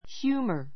humor hjúːmə r ヒュ ーマ 名詞 ❶ ユーモア, おかしさ, 滑稽 こっけい Wit causes sudden laughter, but humor produces a smile.